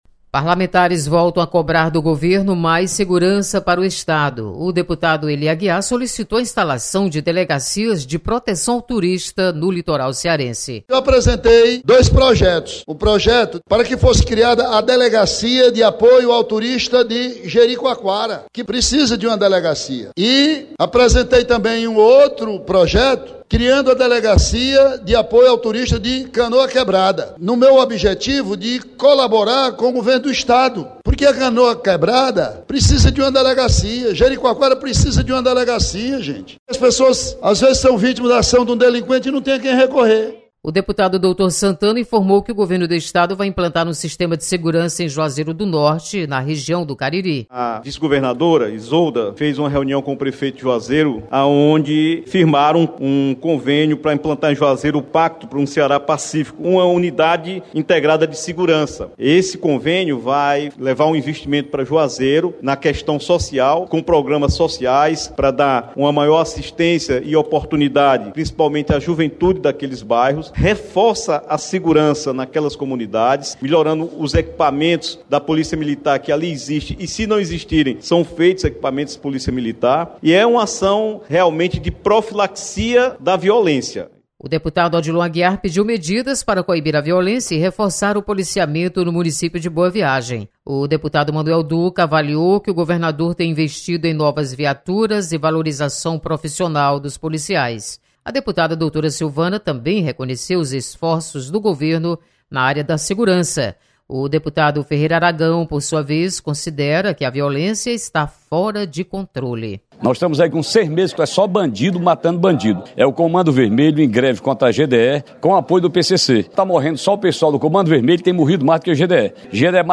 Deputados cobram mais segurança para o Estado. Repórter